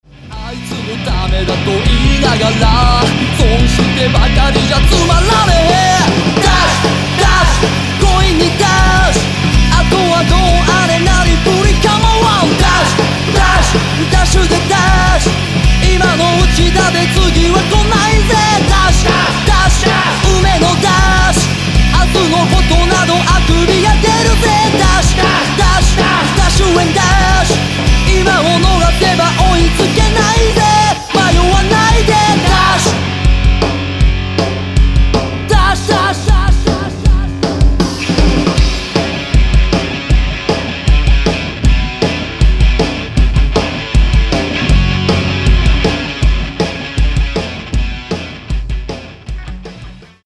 エンターテイメント・パーティ・ハードロックスターバンド!!